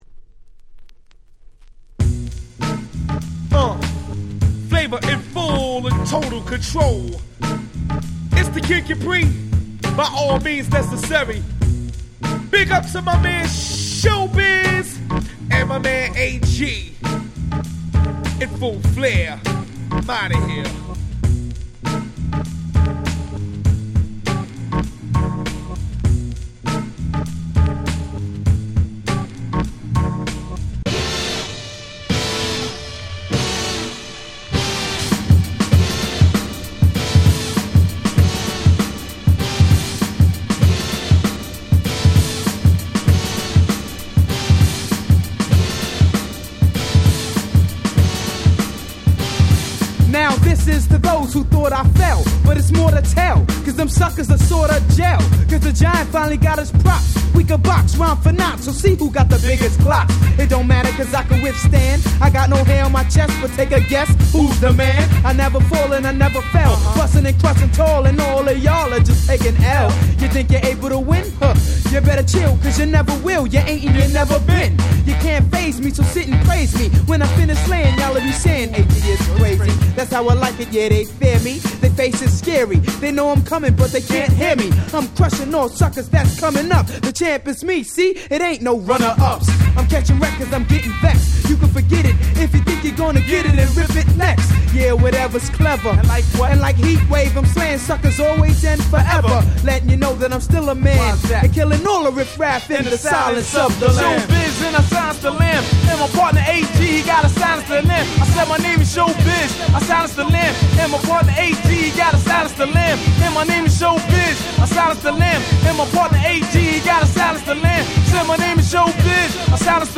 92' Super Hip Hop Classics !!